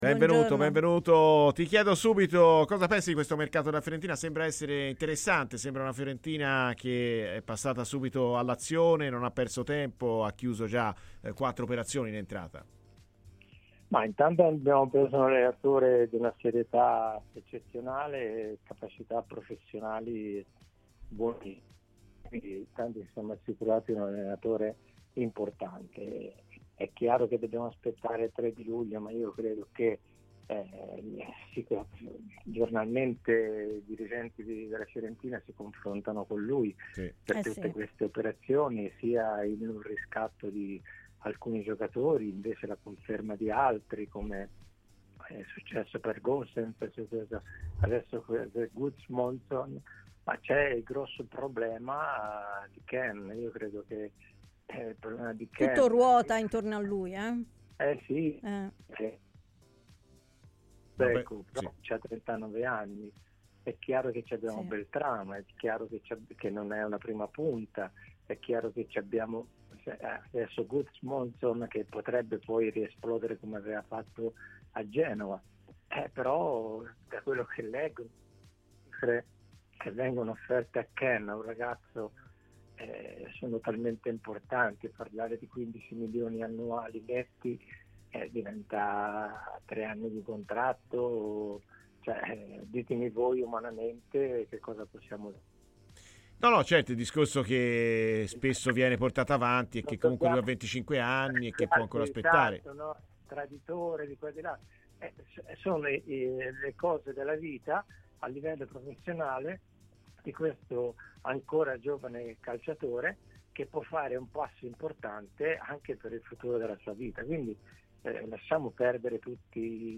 in diretta su Radio FirenzeViola nel corso di "Viola Amore Mio"